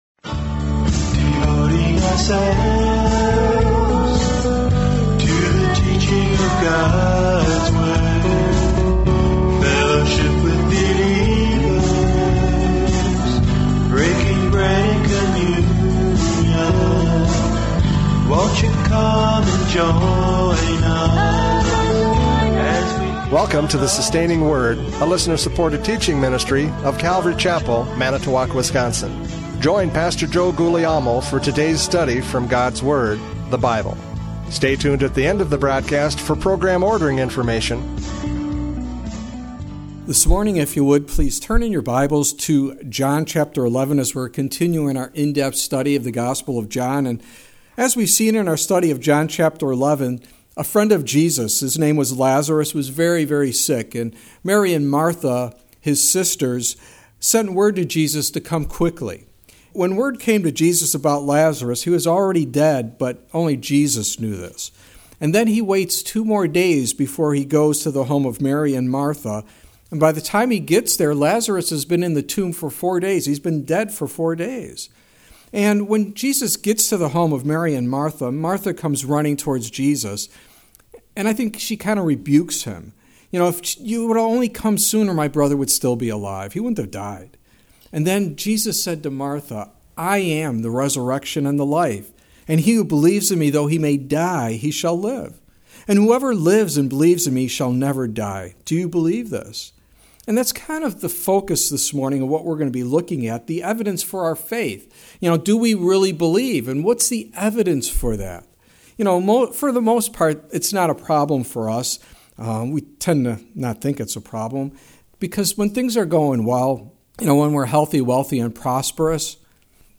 John 11:28-44 Service Type: Radio Programs « John 11:17-27 Resurrection Life!